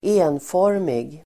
Uttal: [²'e:nfår:mig]